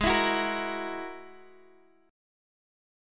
Listen to G#7sus2#5 strummed